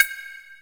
OZ - Perc 2.wav